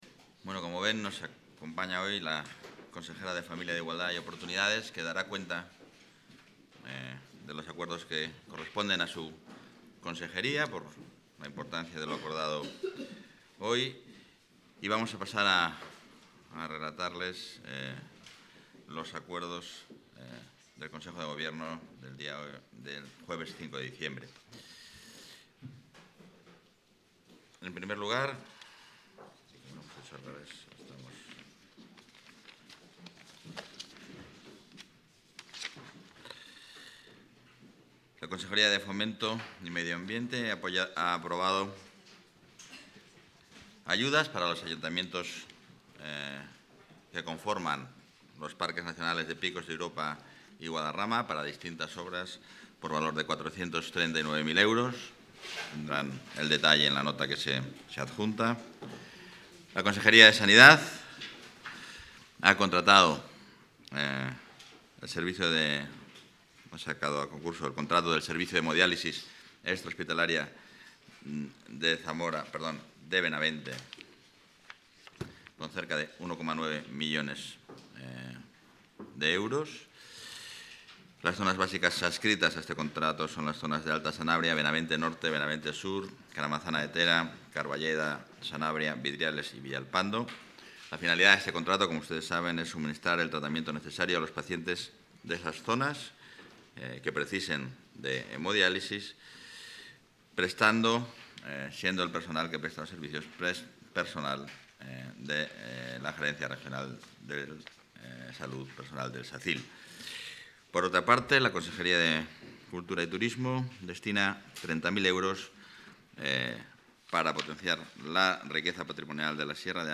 Rueda de prensa tras Consejo de Gobierno.